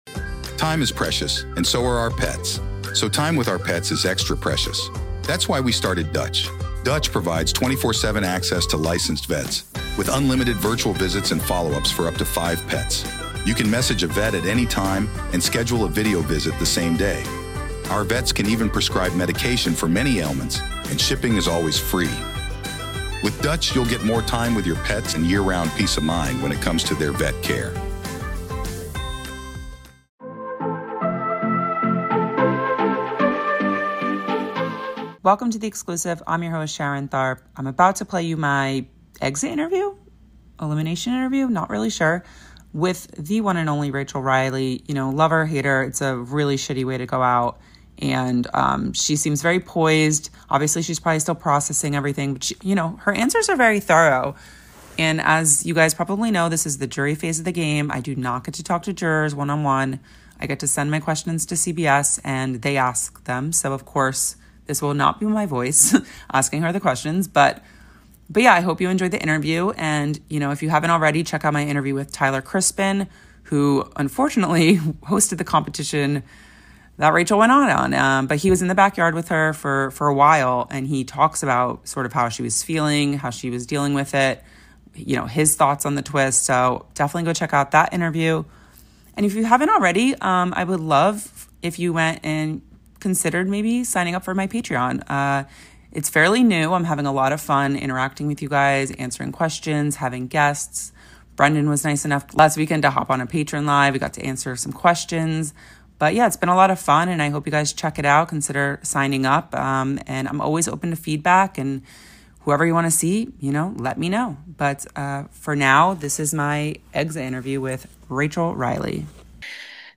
Big Brother 27 Exit Interview: Rachel Reilly Reacts to Shocking Elimination